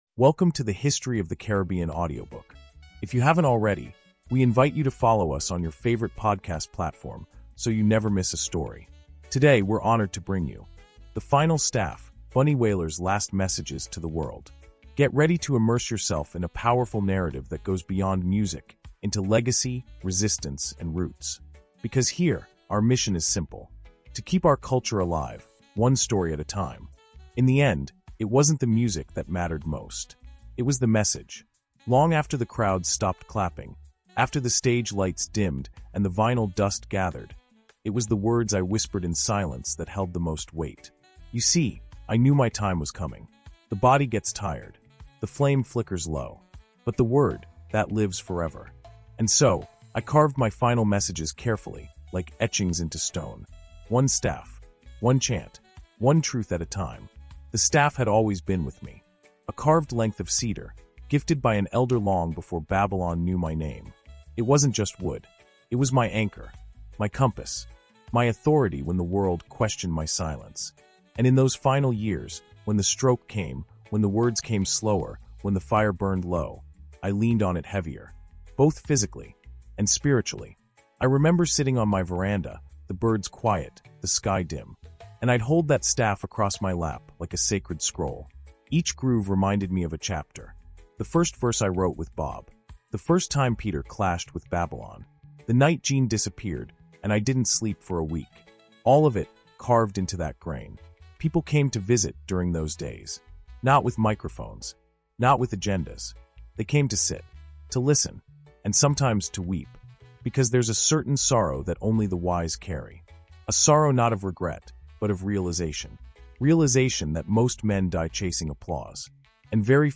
Through intimate narration, Bunny recounts the wisdom he passed down in silence, the truths he carved into wood and memory, and the spiritual clarity he carried in his last days. This episode is a meditation on legacy—not as something preserved in museums, but as something planted in people.